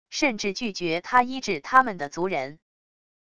甚至拒绝他医治他们的族人wav音频生成系统WAV Audio Player